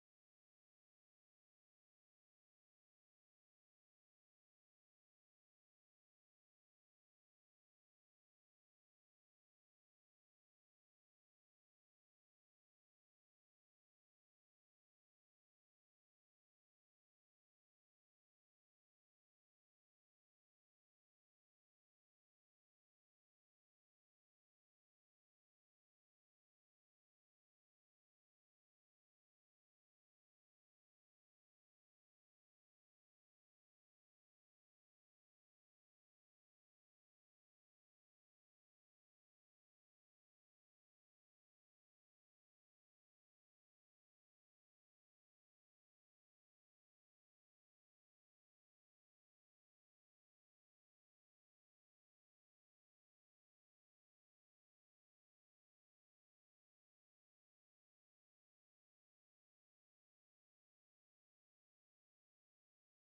Using a blank audio for the story board.